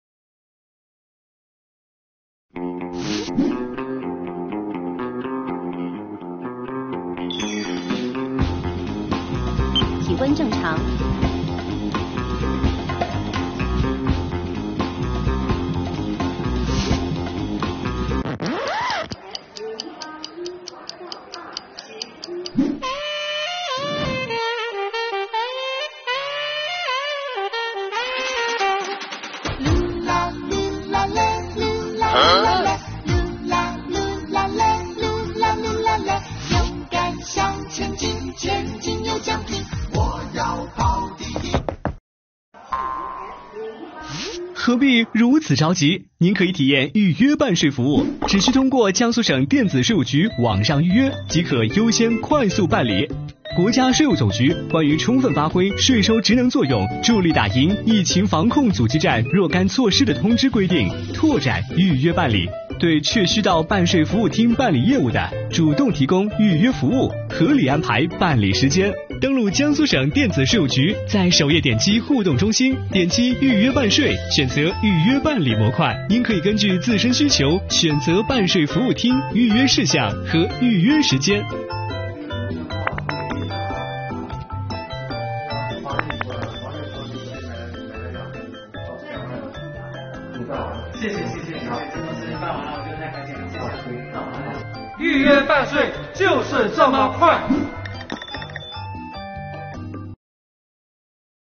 本作品用短短100秒介绍了江苏省电子税务局预约办税的方便快捷，作品画面衔接流畅，场景丰富，节奏和音乐把握恰当，较为新颖。